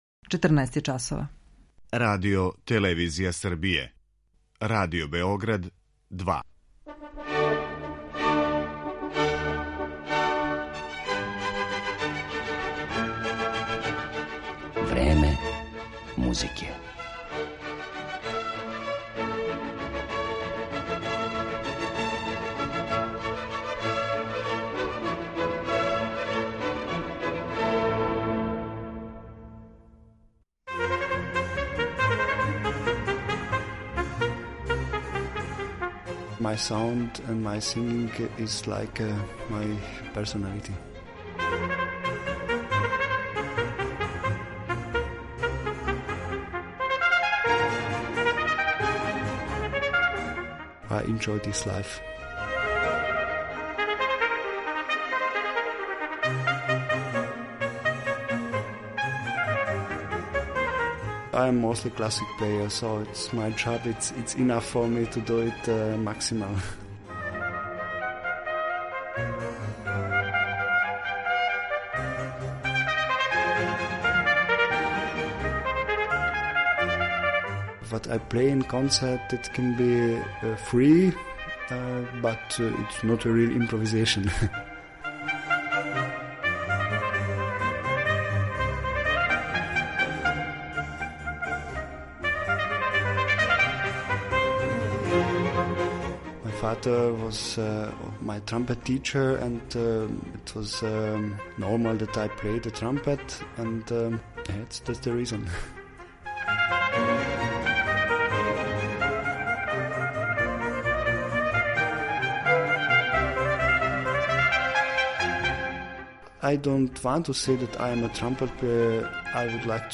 Као „Паганинија на труби” и наследника славног Мориса Андреа, описују критичари једног од најбољих трубача данашњице, мађарског солисту Габора Болдоцког.
Њему је посвећена данашња емисија у којој ћете га слушати како изводи композиције Хенрија Персла, Карла Филипа Емануела Баха, Герга Фридриха Хендла, Волфганга Амадеуса Моцрта и Јохана Себастијана Баха.